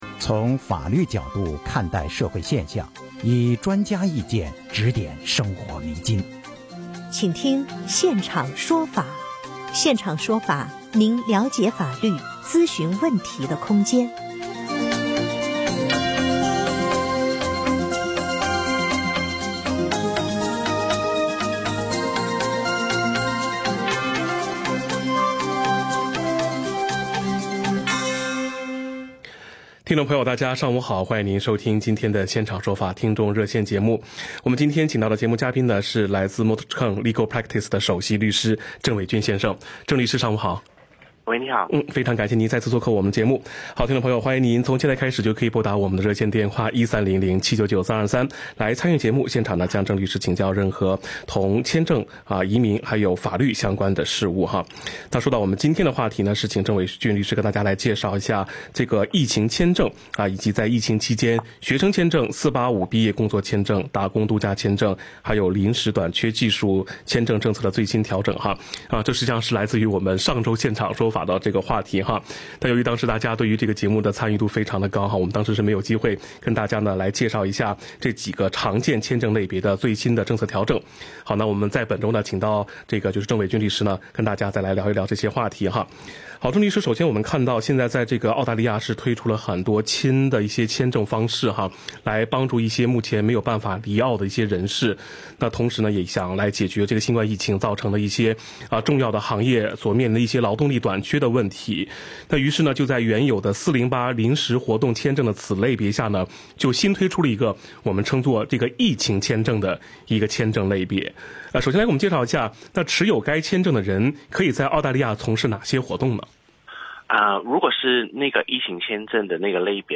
legal_talkback_may_19_new.mp3